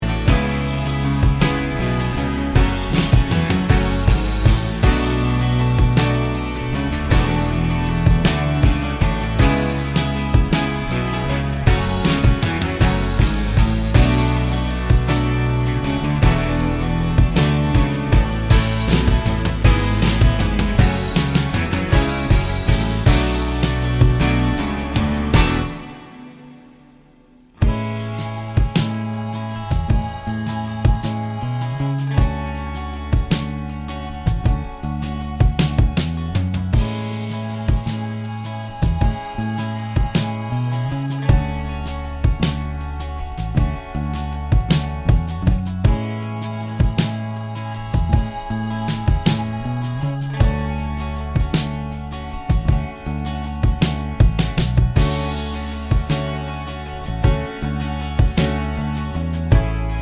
no Backing Vocals Duets 4:10 Buy £1.50